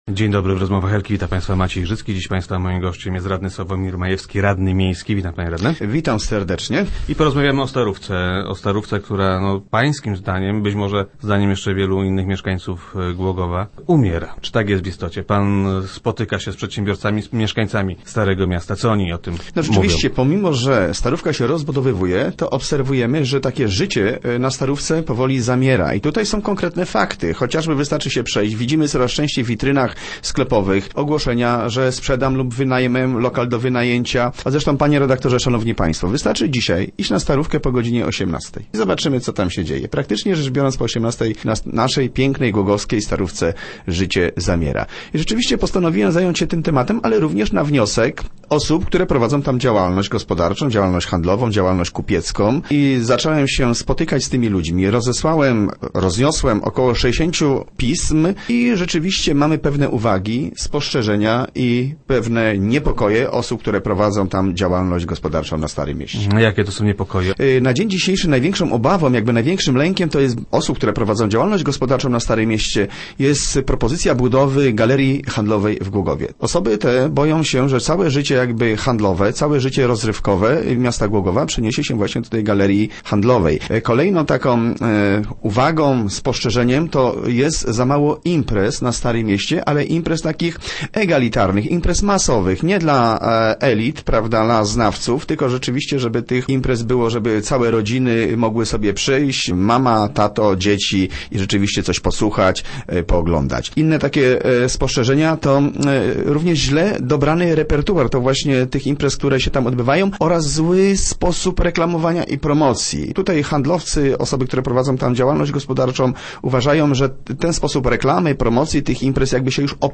O problemie rozmawiał z przedsiębiorcami i mieszkańcami ze Starego Miasta. Radny Majewski był gościem Rozmów Elki.